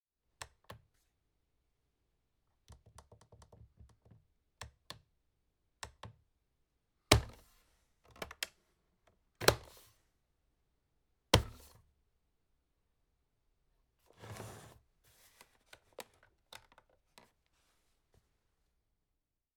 Setting Alarm Clock
Home > Sound Effect > Alarms
Setting_Alarm_Clock.mp3